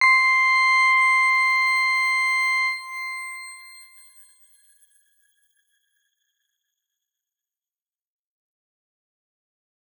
X_Grain-C6-mf.wav